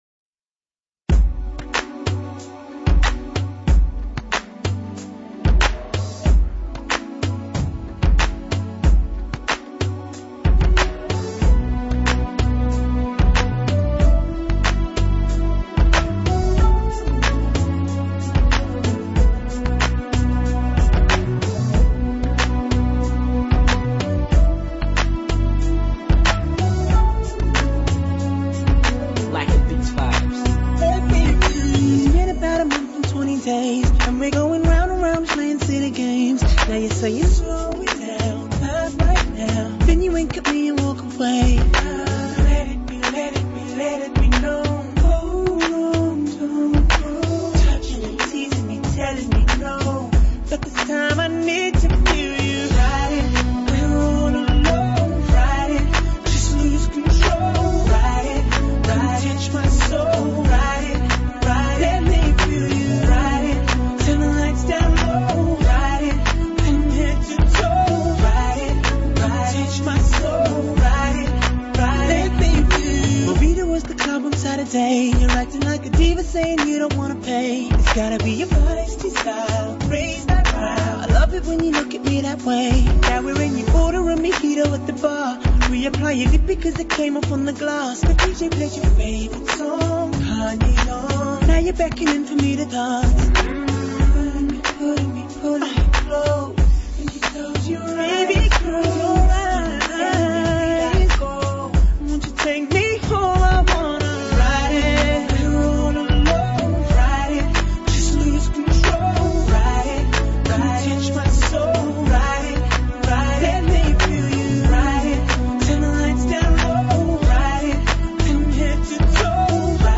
певец, композитор
поп, R&B, Хип-хоп, Соул, Бангра.